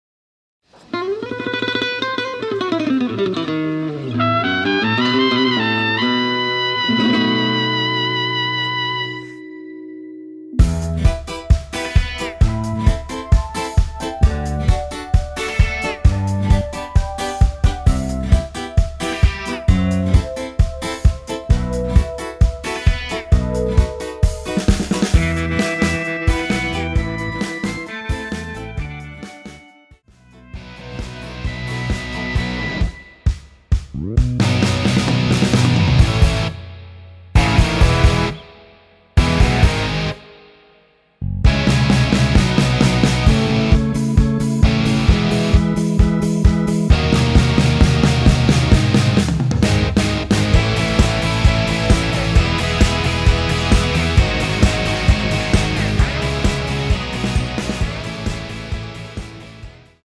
Em
앞부분30초, 뒷부분30초씩 편집해서 올려 드리고 있습니다.
중간에 음이 끈어지고 다시 나오는 이유는